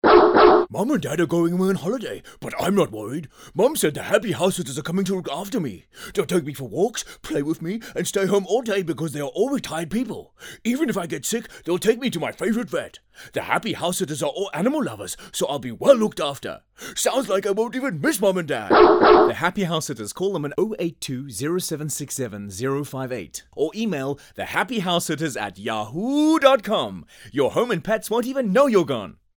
radioadvert.mp3